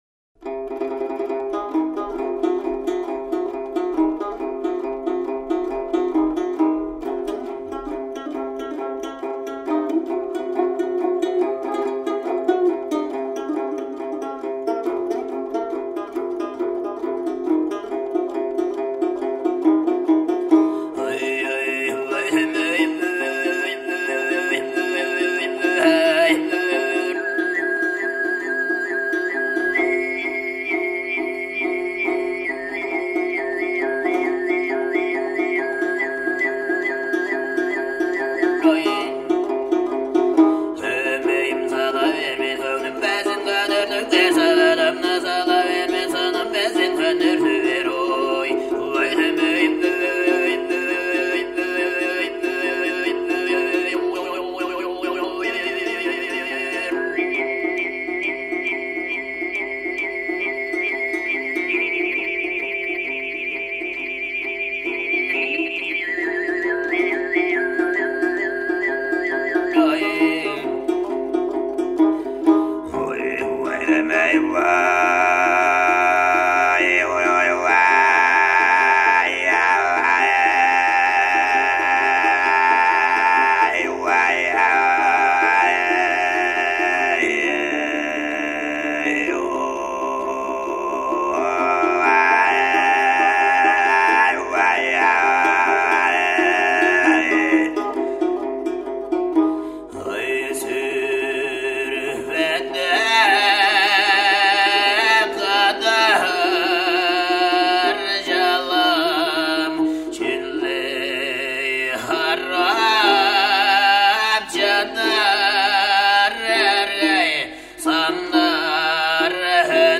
Esempi di canto di gola (per i file audio cliccare con tasto destro e "salva con nome")
Sygyt, Choomej, Kargyraa